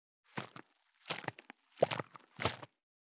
364,500のペットボトル,ペットボトル振る,ワインボトルを振る,水の音,ジュースを振る,シャカシャカ,
ペットボトル